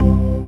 ORGAN-21.wav